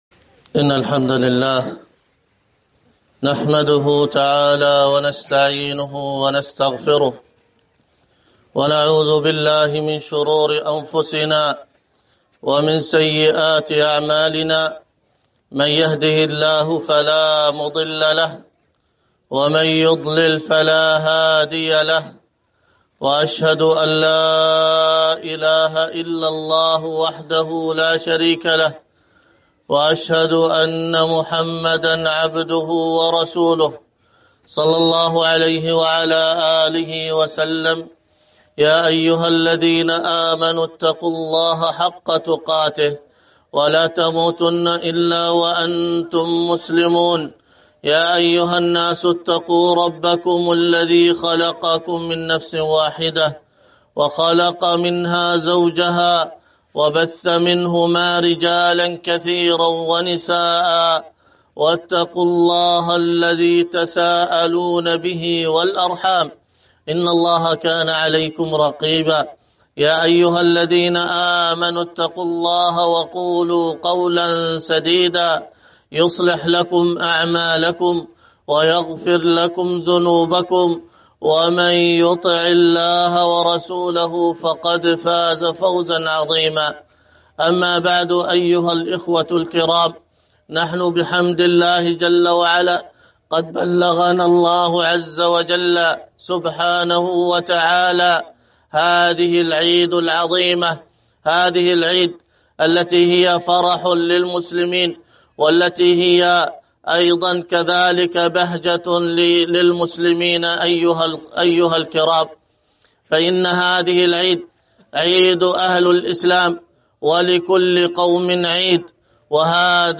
الدرس في القول السديد في مقاصد التوحيد 1، الدرس الأول:من( ترجمة الشارح وصاحب المتن ...